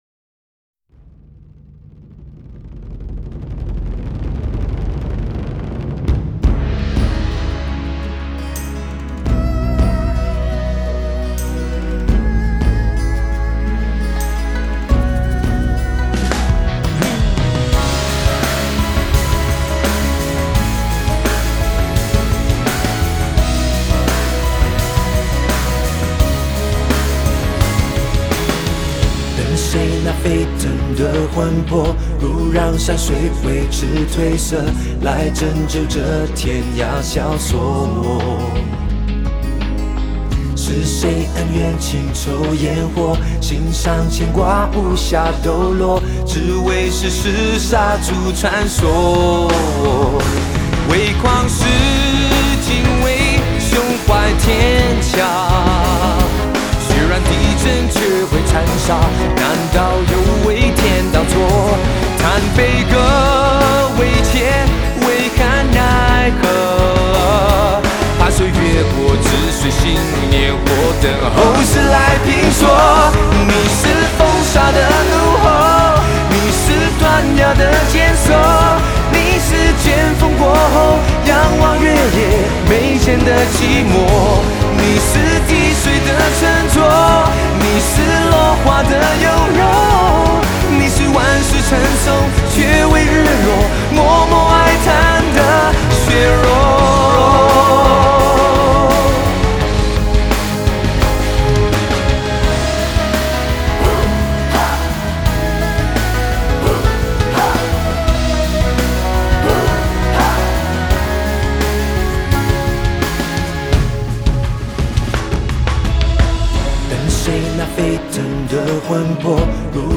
Ps：在线试听为压缩音质节选，体验无损音质请下载完整版 等谁那沸腾的魂魄 如让山水为之褪色 来拯救这天涯萧索 .